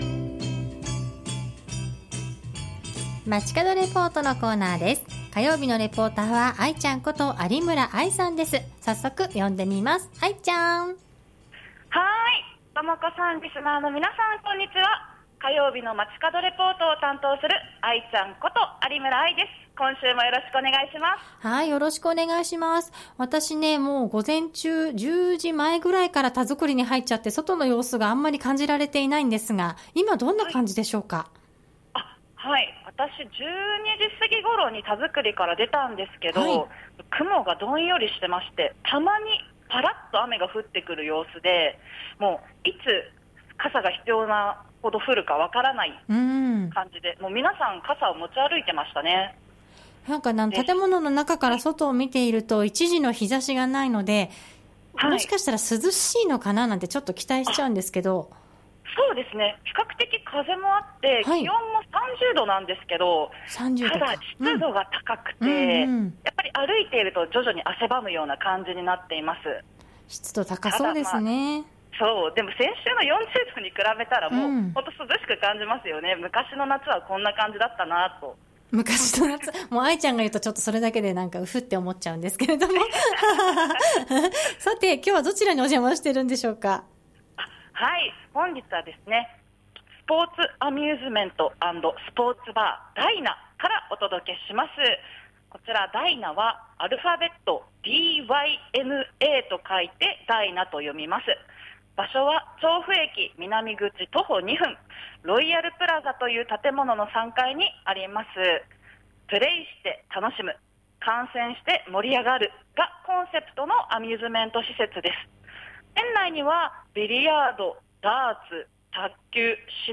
本日は調布駅南口から徒歩2分にあるスポーツアミューズメント&スポーツバー「DYNA【ダイナ】」からお届けしました。